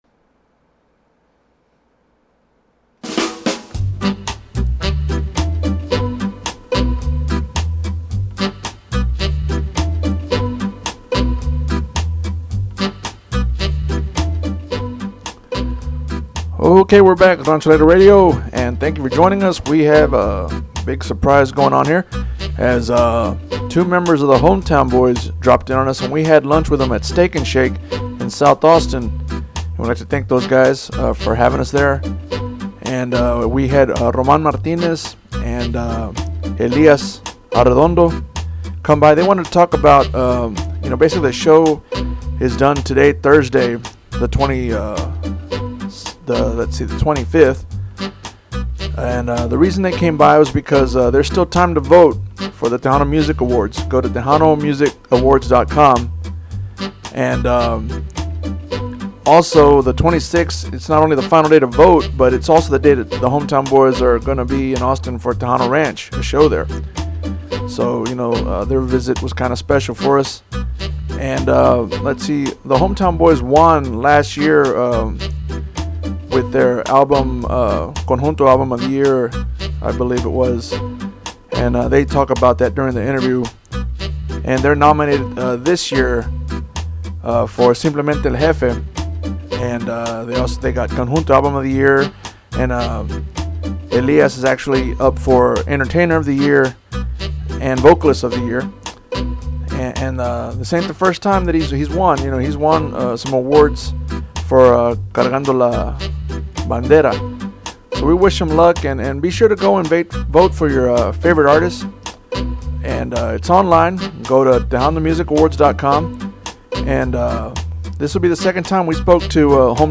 Rancho Alegre Interview